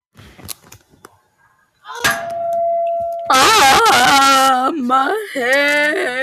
MY HEAD AHHHHH Play and download MY HEAD AHHHHH sound effect.
voice record soundboard